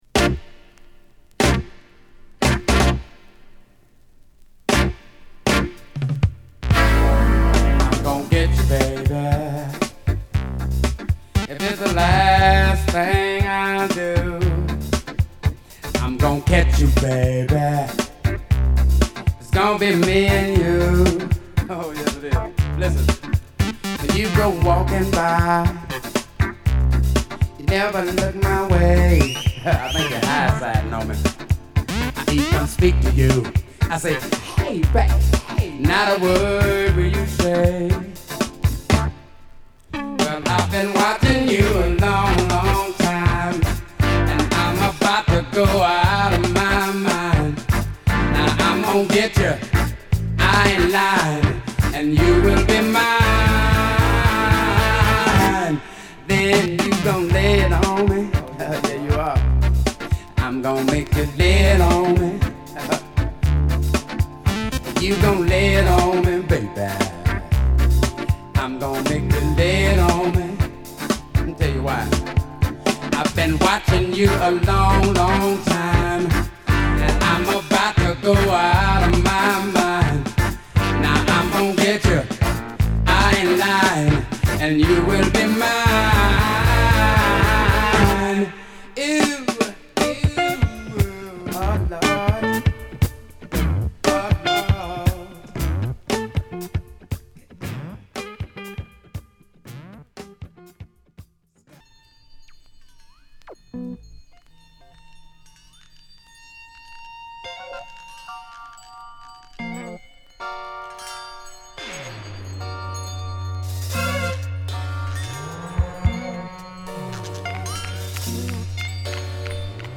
一聴して分かる独特のギターと、イイ意味で抜けたメロディ/ヴォーカルが心地良い好作！